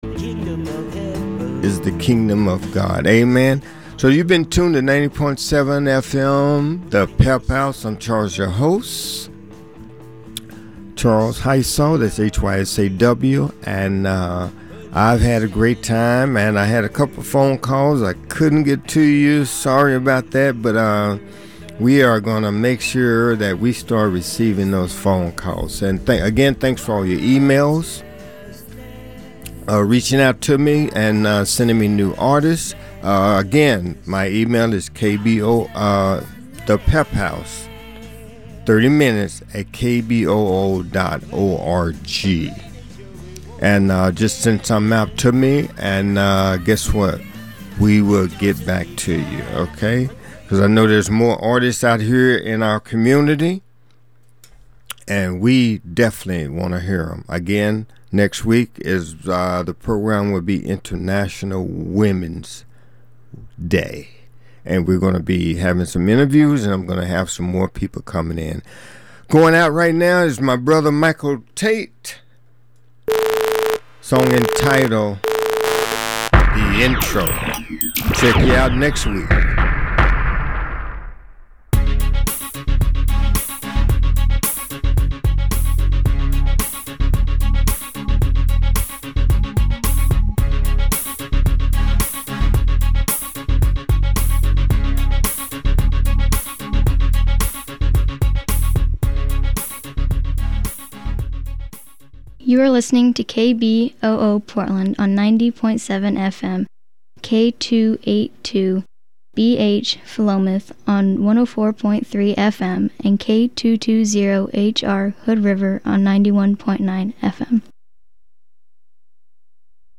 Conversations with leaders in personal and cultural transformation